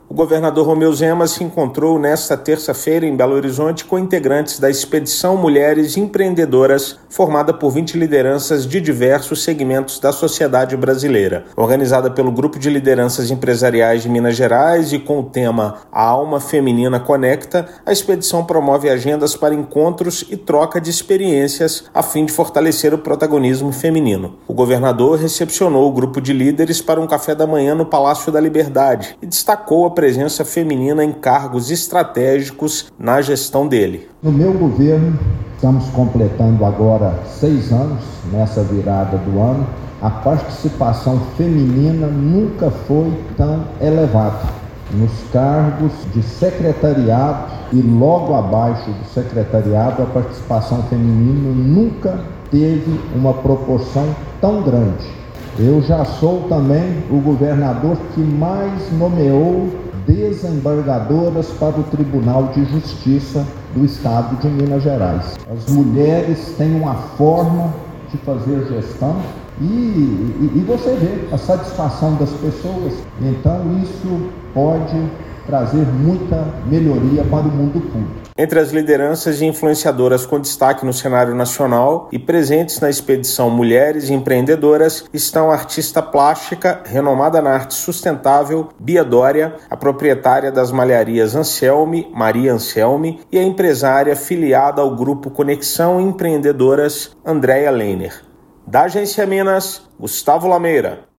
Chefe do Executivo estadual recebeu para um café da manhã 20 empreendedoras e líderes em suas áreas de atuação de todo o país. Ouça matéria de rádio.